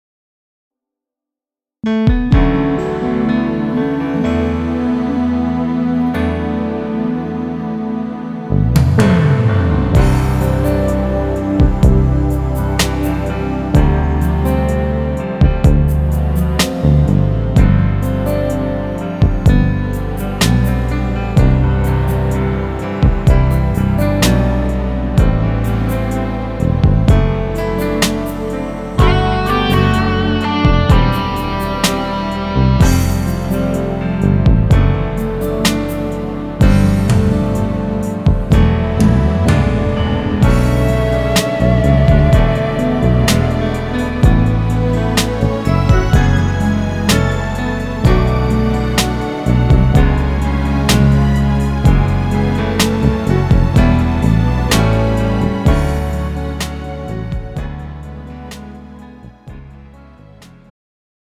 음정 -3키
장르 축가 구분 Pro MR